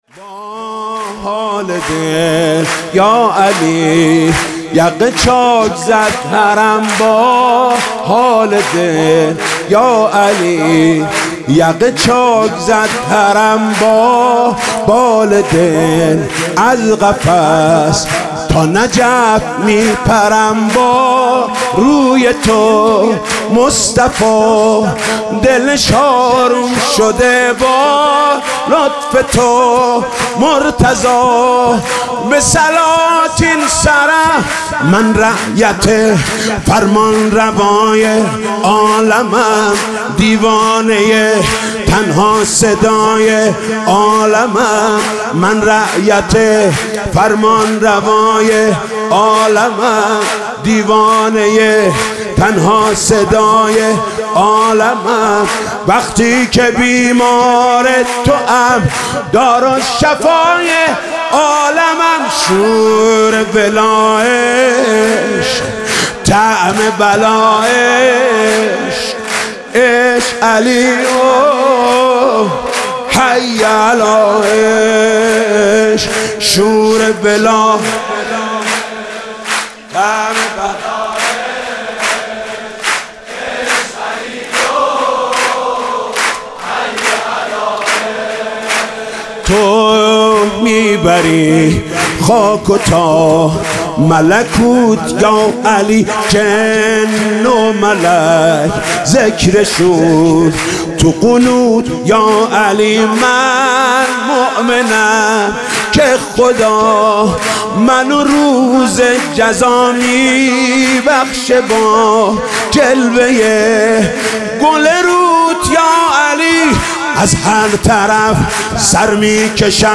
سرود: با حال دل یا علی، یقه چاک زد حرم